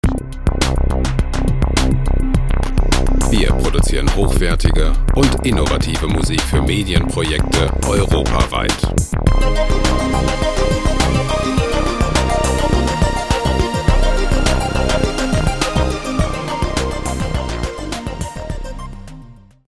Electro Loops
Musikstil: Electro Pop
Tempo: 104 bpm